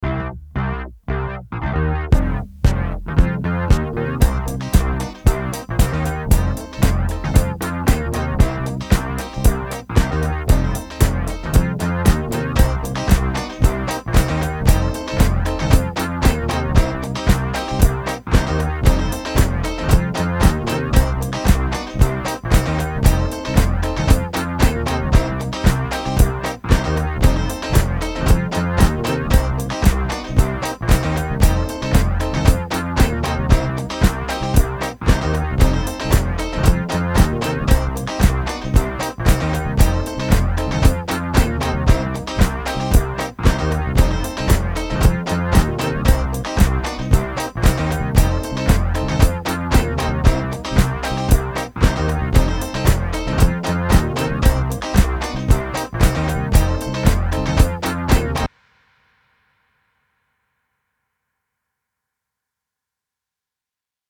Home > Music > Blues > Bright > Smooth > Laid Back